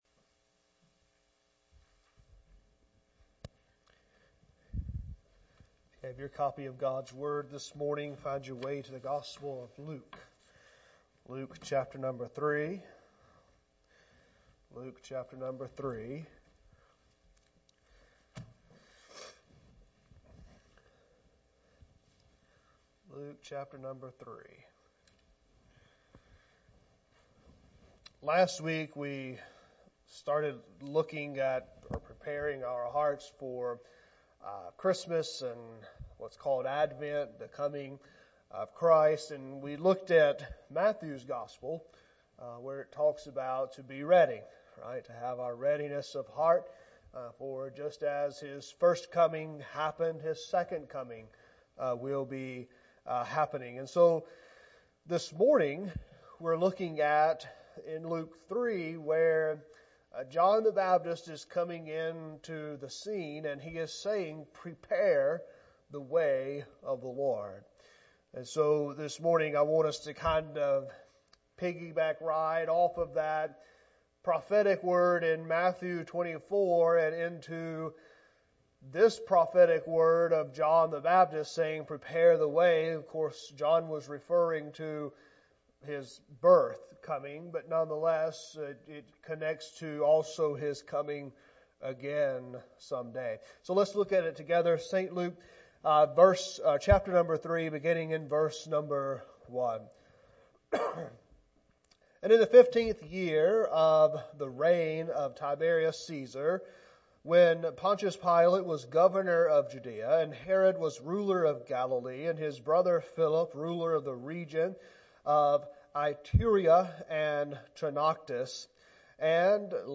Sermons | West Acres Baptist Church